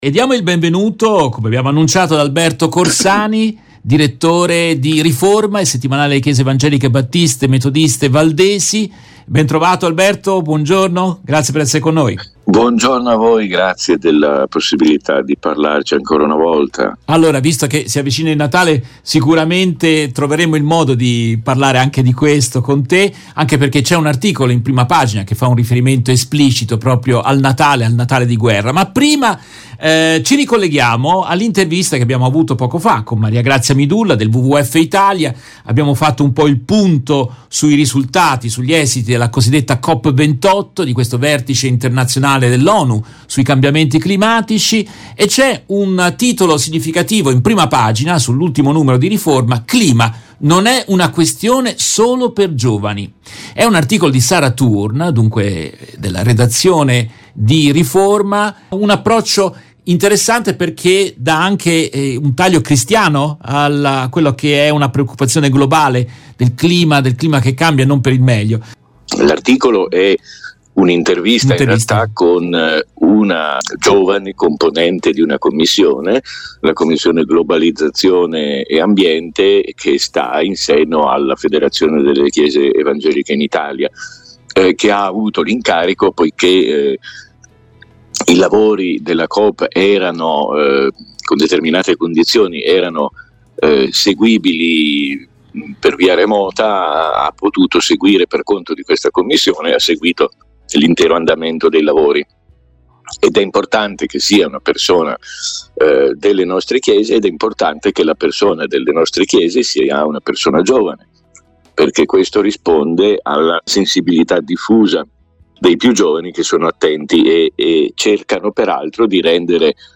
Continuano le conversazioni